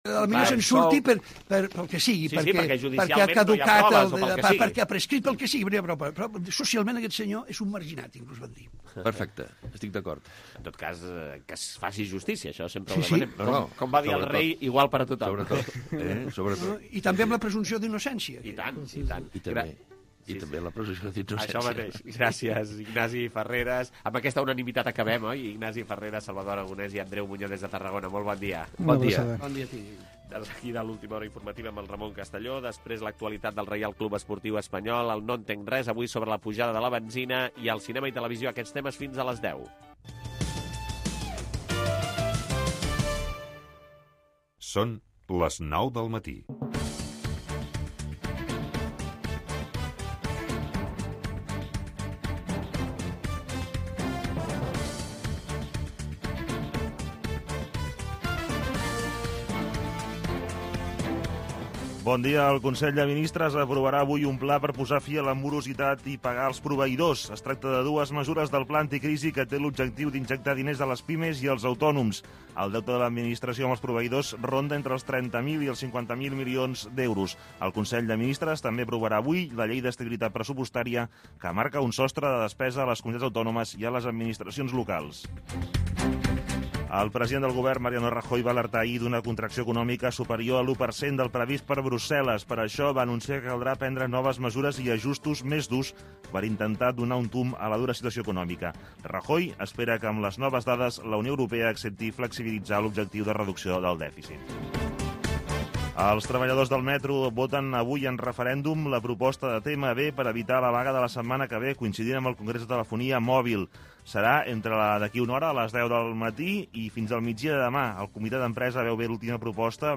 El primer cafè. Informació, actualitat, espais, 2 hores d'un magazin matinal